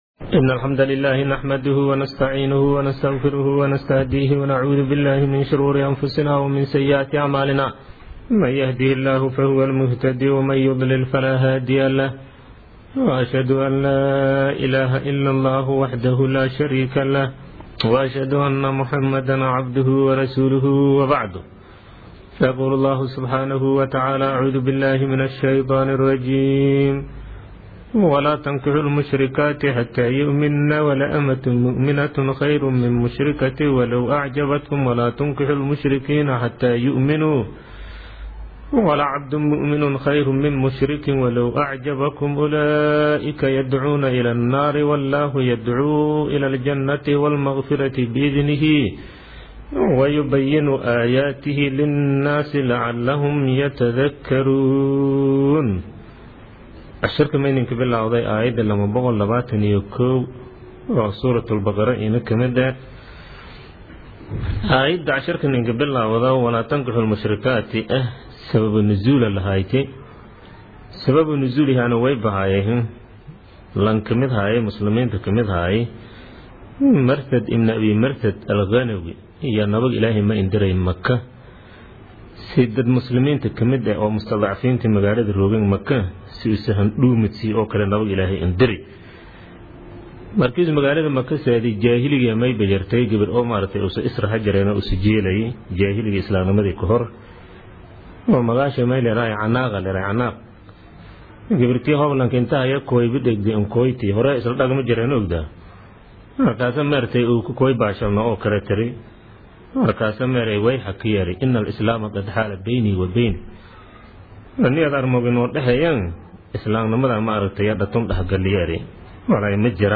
Casharka Tafsiirka